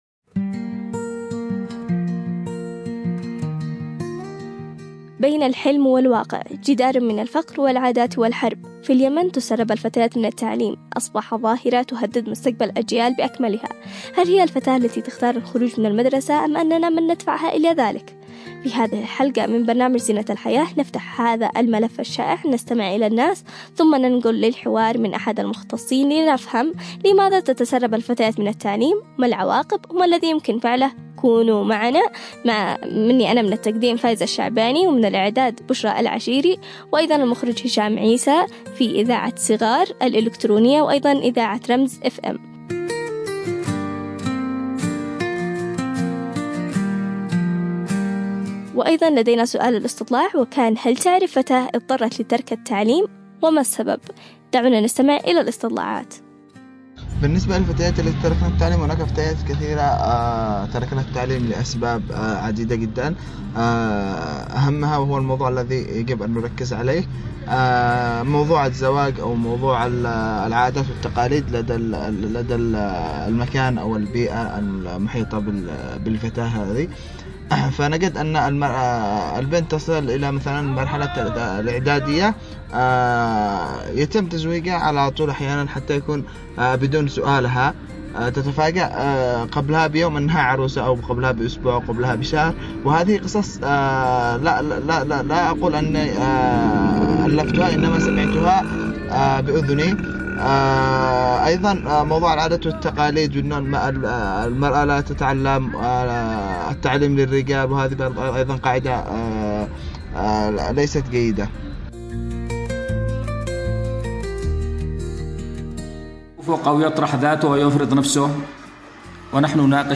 في نقاش حول أسباب تسرب الفتيات من التعليم، وانعكاساته النفسية والاجتماعية والاقتصادية، وسبل الحد من هذه الظاهرة.
📻 عبر أثير إذاعة رمز